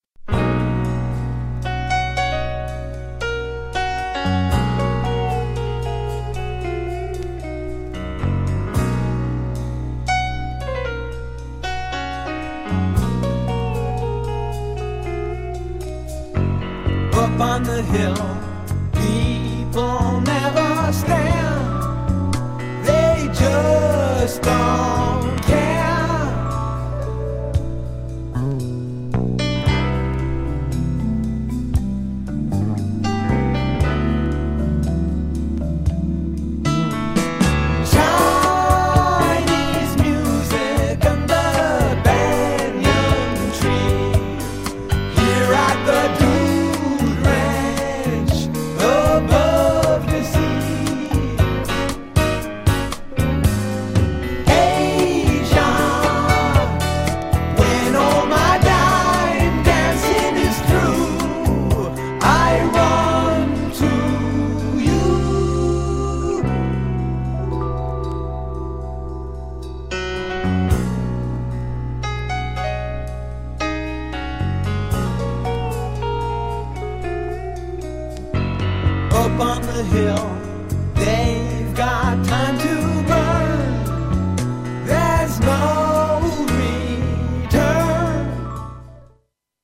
GENRE Dance Classic
BPM 116〜120BPM
AOR
JAZZY
クロスオーバー
ロック # 洗練された